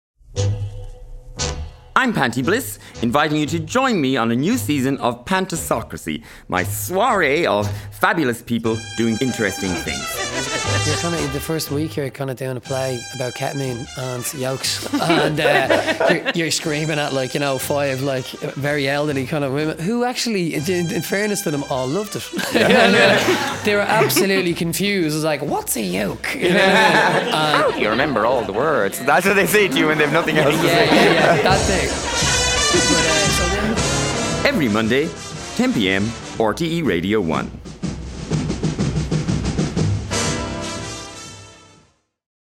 Panti Bliss hosts a cabaret of conversations with, and about, contemporary Ireland.